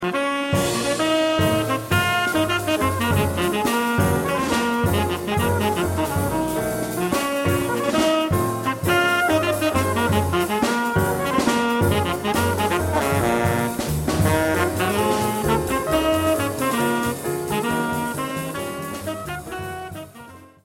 a medium up swing with a memorable melody